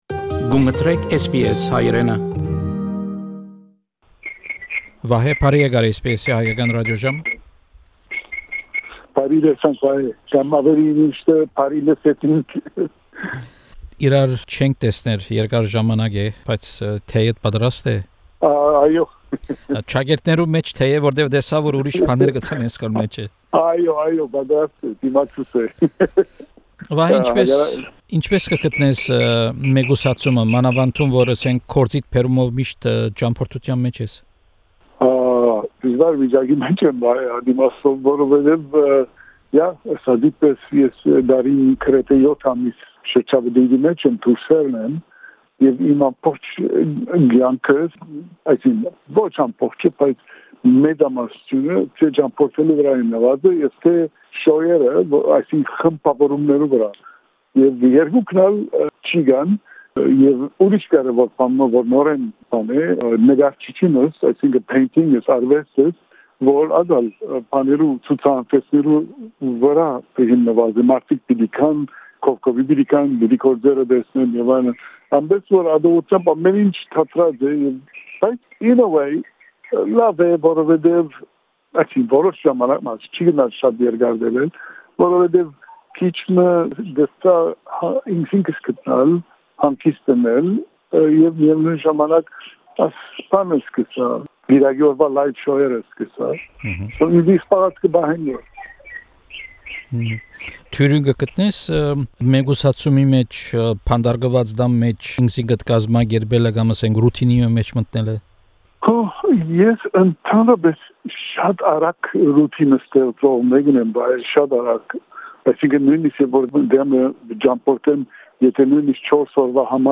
A candid conversation with Vahe Berberian